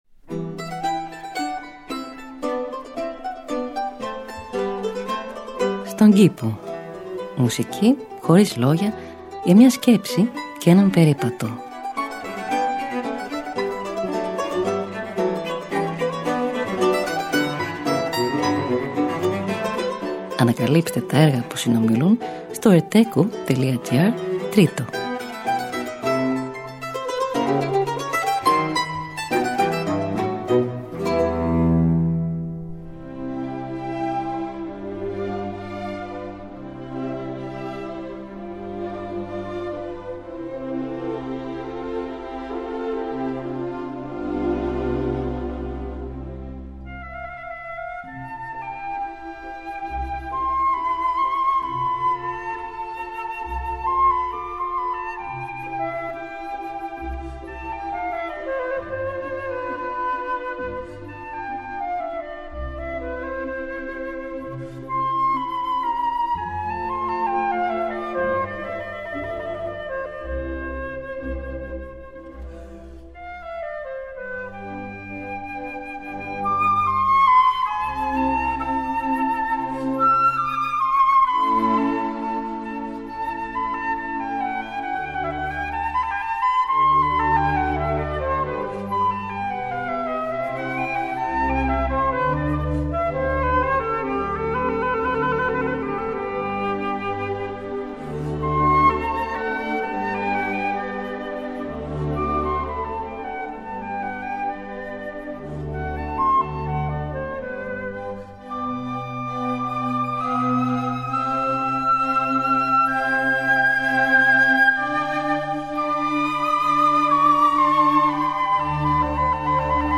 Μουσική Χωρίς Λόγια για μια Σκέψη και έναν Περίπατο.